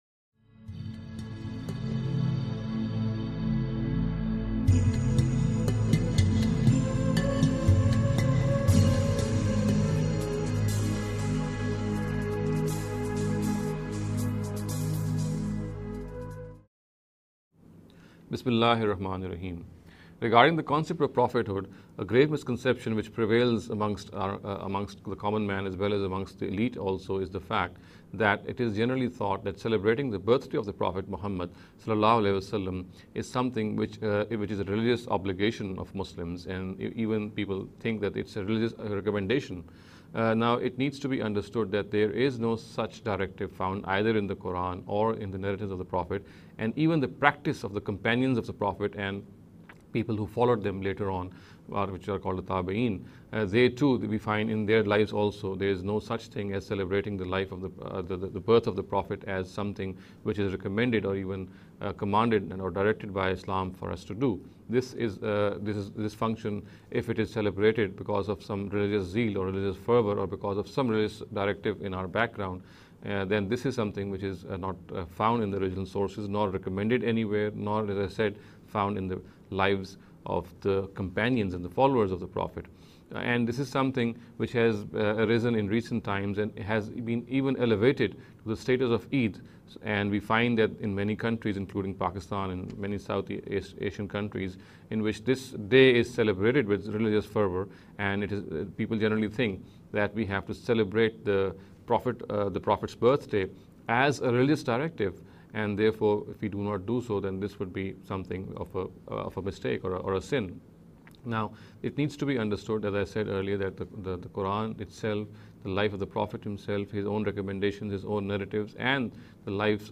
In this series of short talks